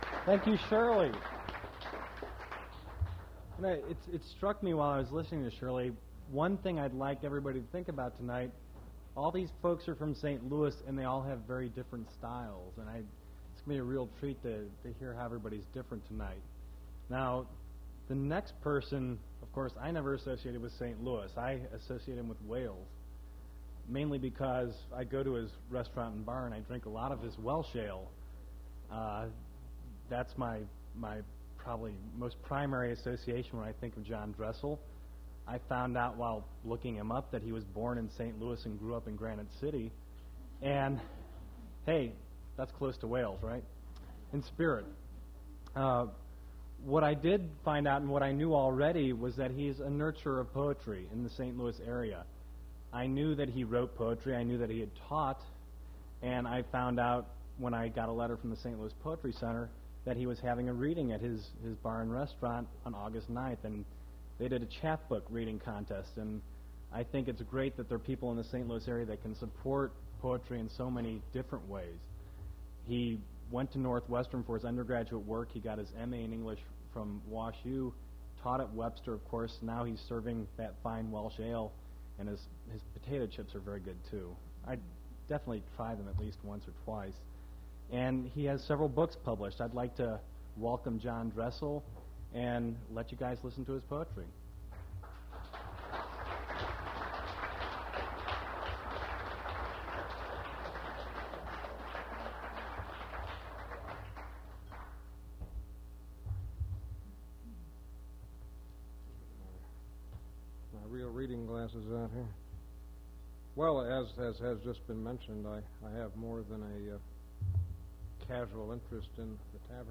Poetry reading
poetry reading at Duff's Restaurant
generated from original audio cassette